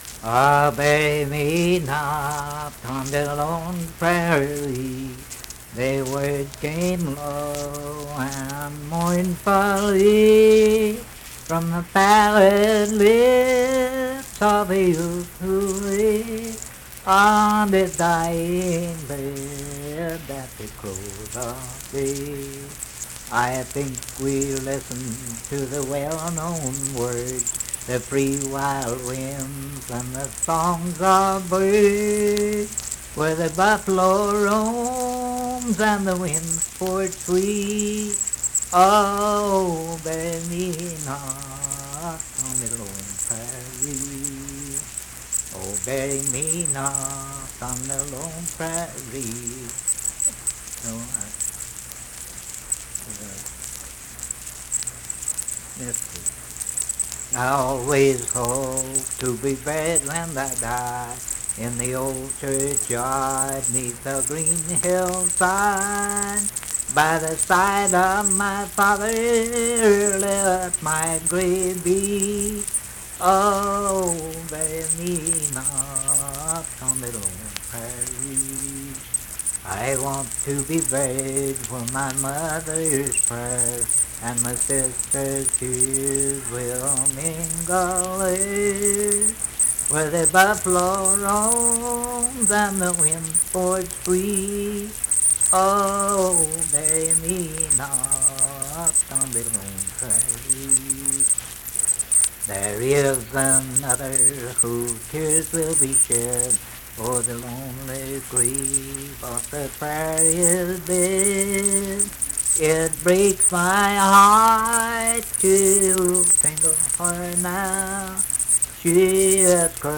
Lone Prairie - West Virginia Folk Music | WVU Libraries
Unaccompanied vocal music
Voice (sung)
Cabell County (W. Va.), Huntington (W. Va.)